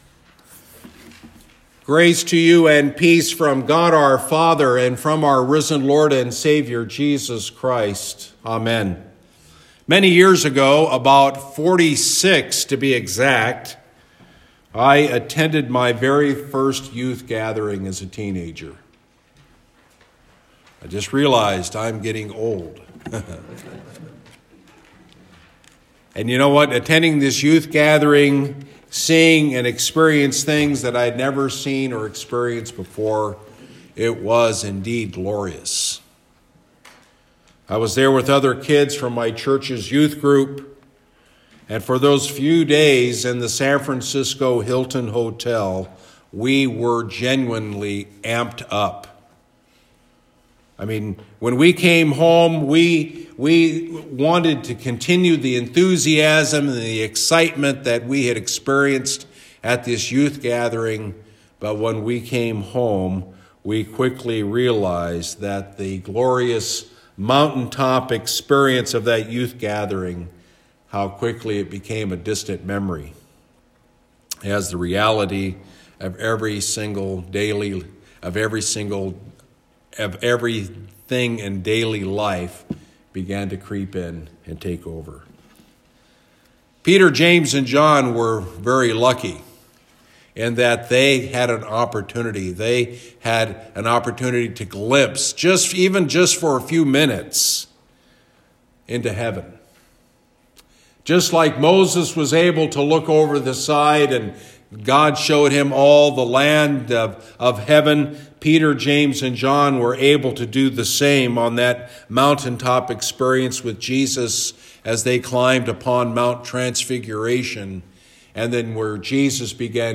TRANSFIGURATION SUNDAY Sermon from Christ Lutheran Church of Chippewa Falls, WI Text: Deuteronomy 34; Hebrews 3:1-6; Luke 9:28-36